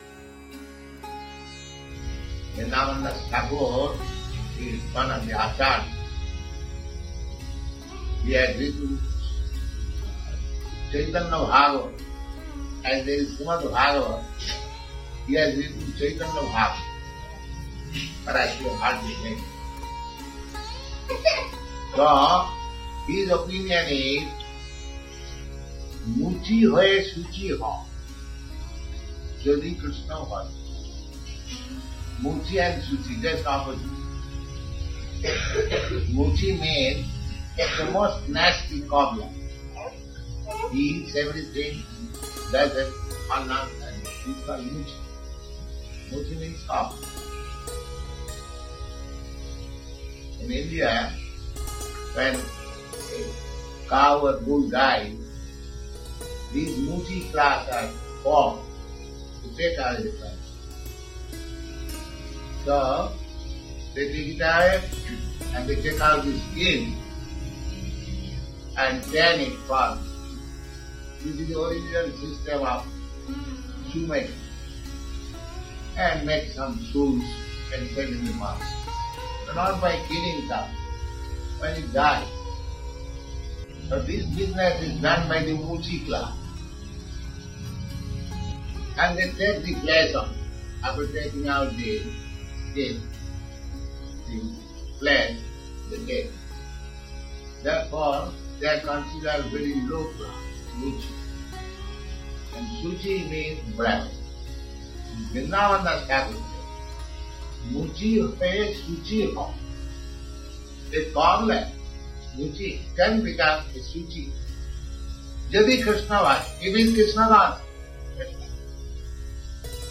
(691226 - Lecture Initiation - Boston)